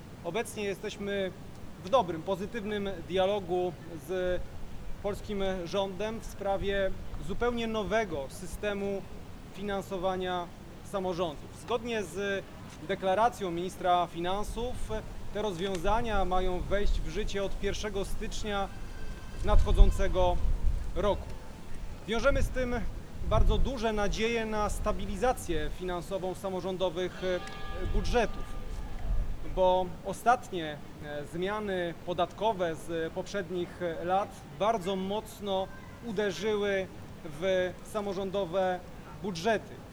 Podejmowane są działania nad poprawą budżetów miast. – Od 1 stycznia 2025 roku wejdzie w życiu nowy system finansowania miast – zaznacza Krzysztof Kosiński, prezydent Chocianowa.